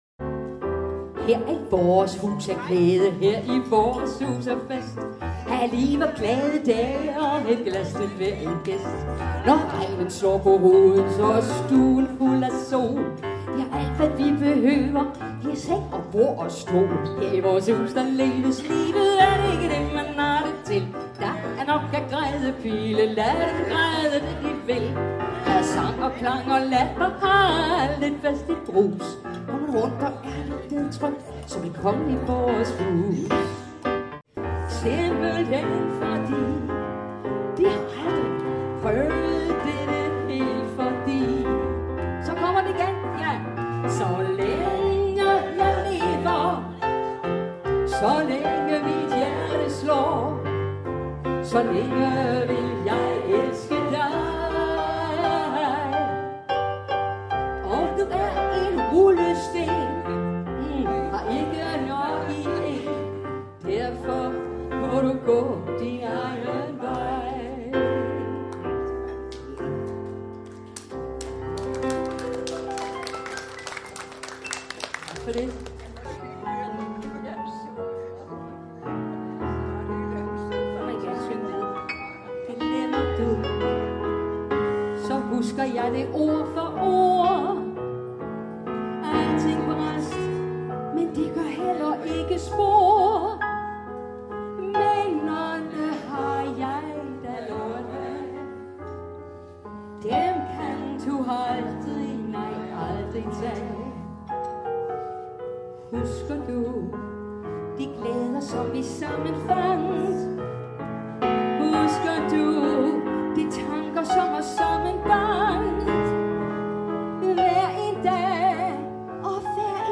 afdæmpet hyggelig jazz og kendte ballader